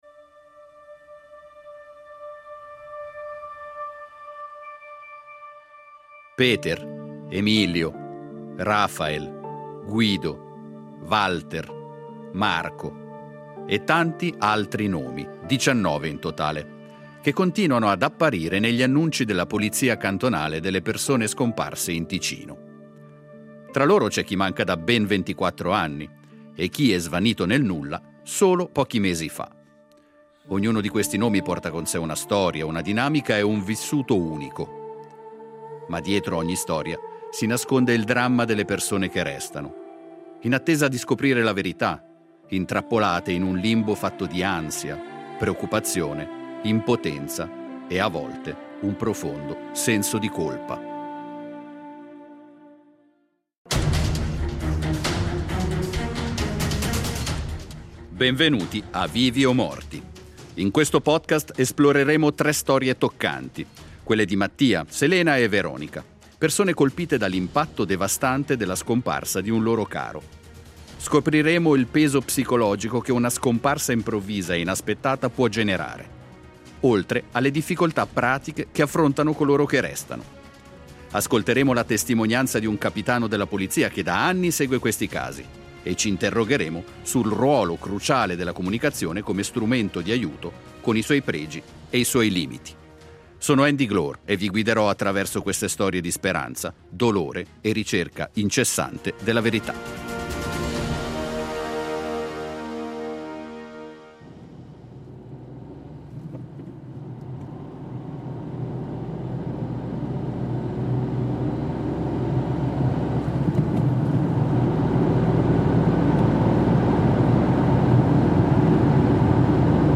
“ Vivi o morti” è un podcast che scava nel silenzio delle persone scomparse in Ticino, tra indagini senza corpo, leggi che non aiutano e famiglie intrappolate in un limbo. Tre storie vere: un padre che svanisce lasciando solo interrogativi, una zia amata che scompare nel nulla, un figlio fragile che fugge e torna cambiato. Con le voci di chi ha vissuto l’attesa sulla pelle, investigatori, psicologi e attivisti